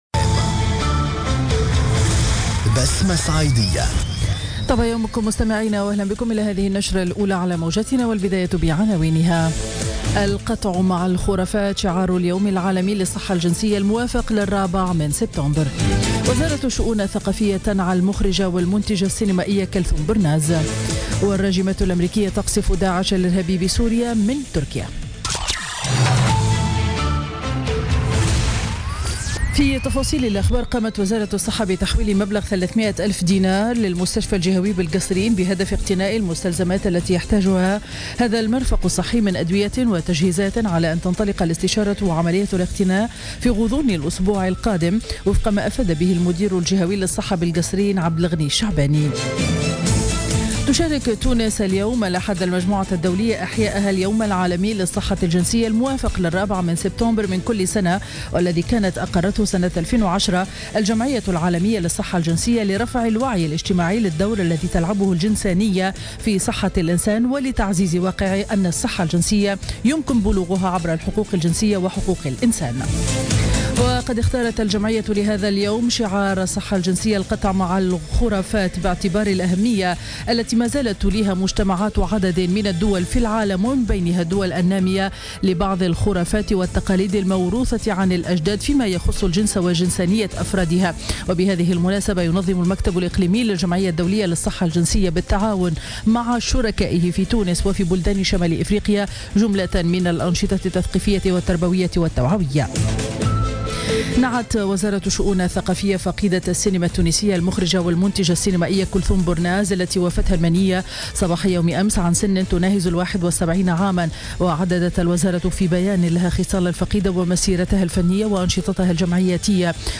نشرة أخبار السابعة صباحا ليوم الأحد 4 سبتمبر 2016